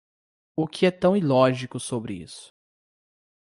Read more illogical (contrary to logic; lacking sense or sound reasoning) Frequency 34k Pronounced as (IPA) /iˈlɔ.ʒi.ku/ Etymology From i- + lógico.